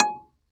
CLV_ClavDBG#6 3a.wav